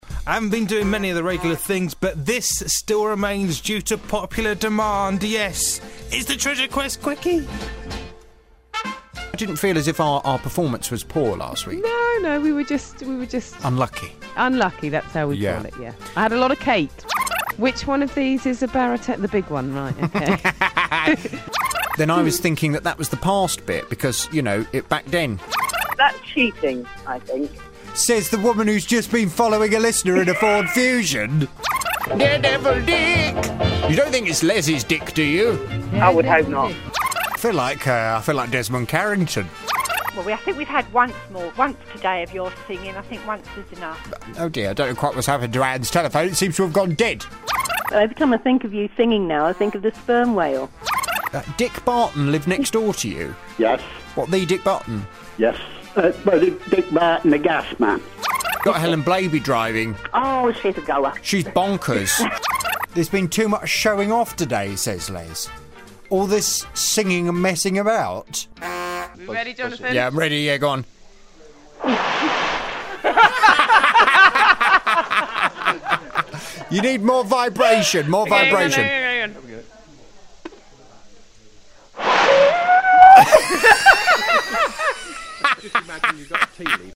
3 hours of BBC Radio Northampton's Treasure Quest in around a minute.